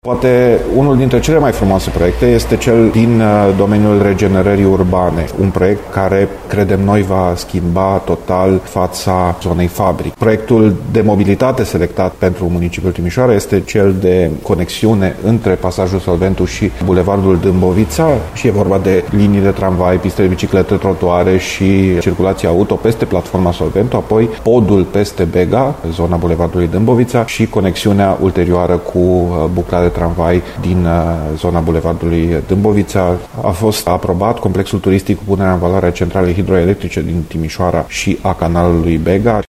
Printre acestea se numără și proiectul de regenerare urbană din zona Frabric, spune viceprimarul în exercițiu Dan Diaconu.